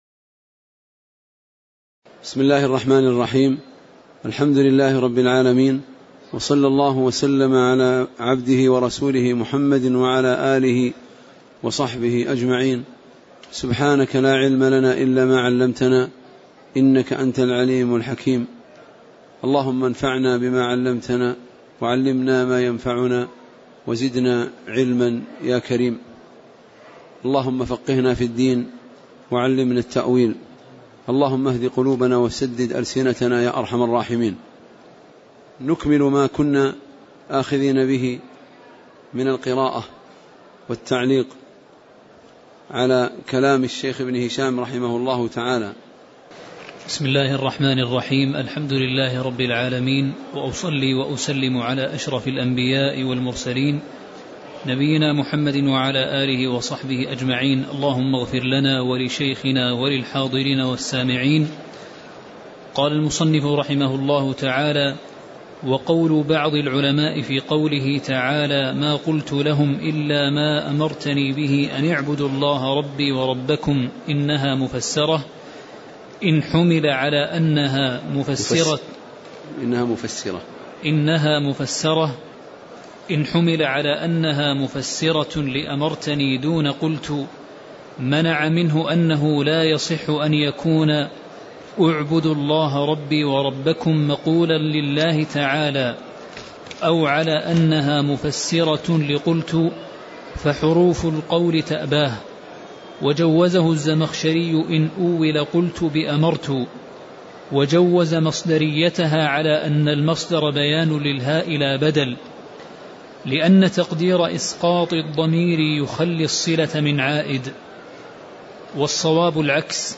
تاريخ النشر ٩ شوال ١٤٣٨ هـ المكان: المسجد النبوي الشيخ